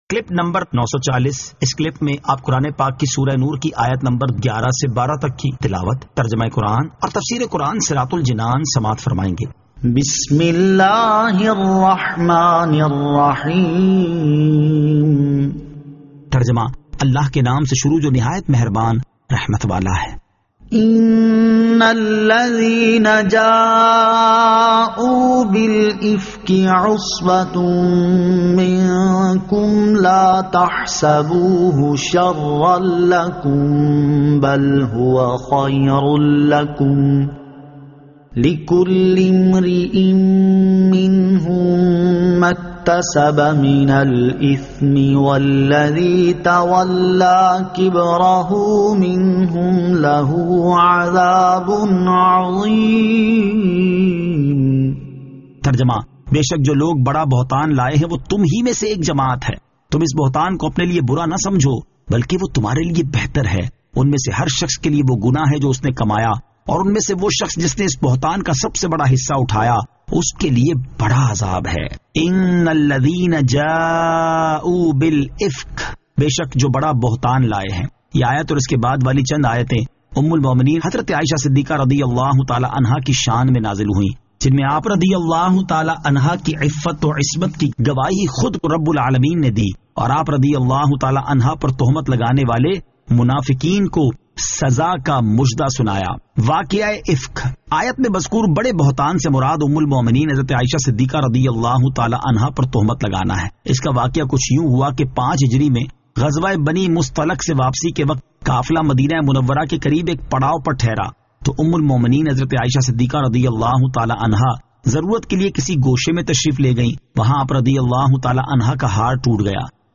Surah An-Nur 11 To 12 Tilawat , Tarjama , Tafseer